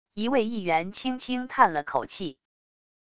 一位议员轻轻叹了口气wav音频